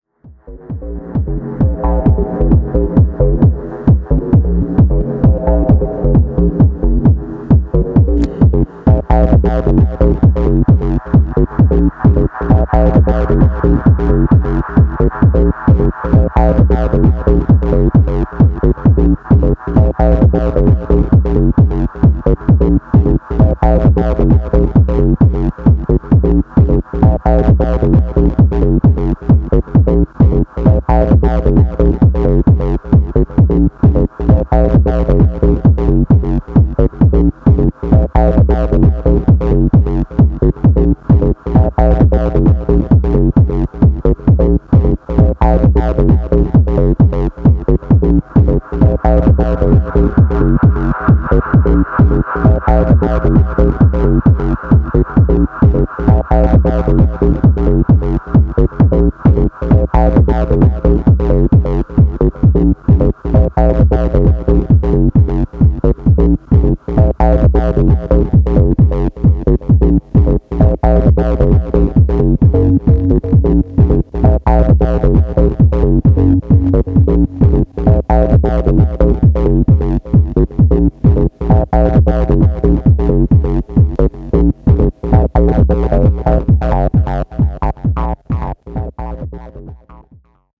初期シカゴ・ハウスのアブストラクトな未分化グルーヴがダブ・テクノマナーで調理された